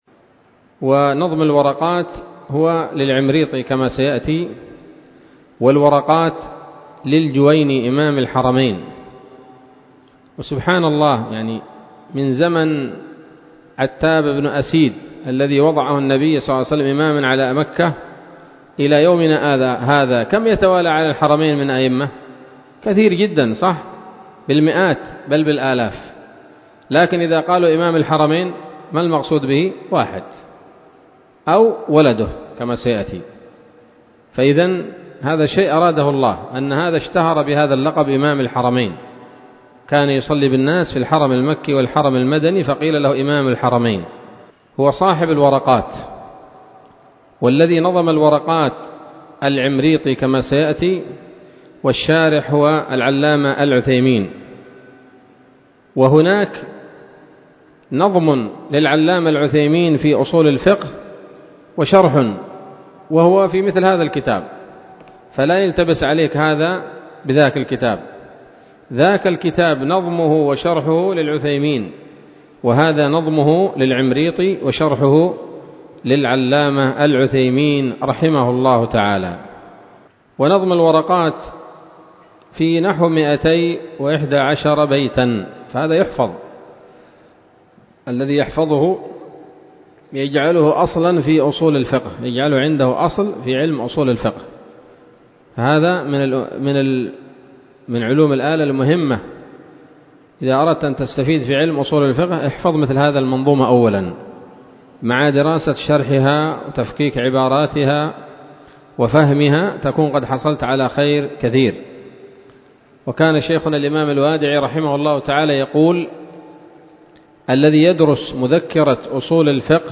الدرس الأول من شرح نظم الورقات للعلامة العثيمين رحمه الله تعالى